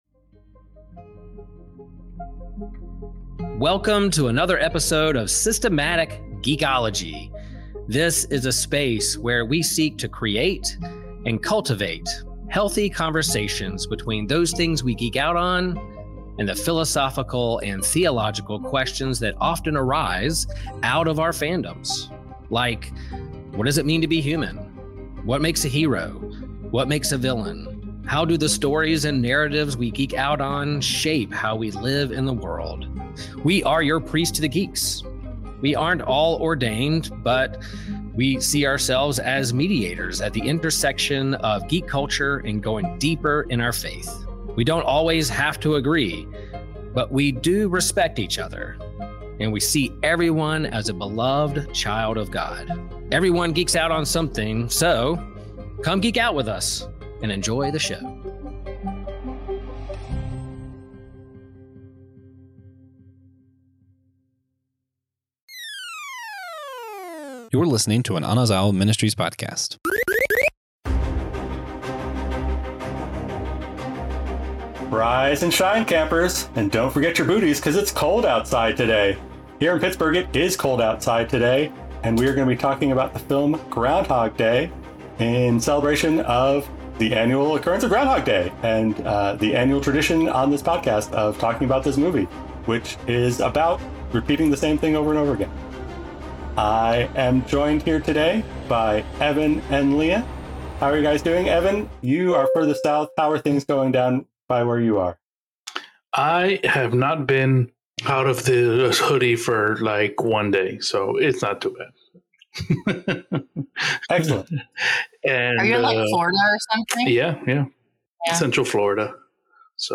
The trio kicks off with a lighthearted banter about their latest 'geek-outs,' including revivals of beloved shows and upcoming films.
The episode is rich with humor, witty exchanges, and insightful commentary on the nature of storytelling, leaving listeners both entertained and reflective on their own experiences with unresolved narratives.